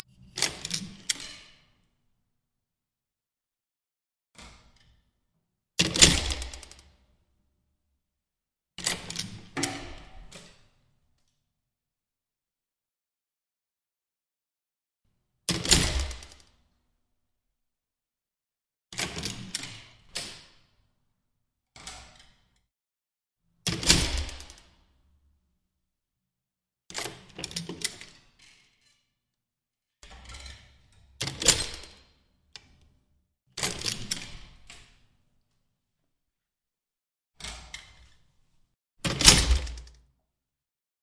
挂锁 链锁解锁
描述：重链，挂锁穿过金属门，解锁。钥匙拨浪鼓，脚步声，再次锁定，链条拨浪鼓。
标签： 安全 金属 关闭 金属 钥匙 打开门 铮铮 运动 监狱 细胞 金属 打开 关闭 开启 关闭 拨浪鼓
声道立体声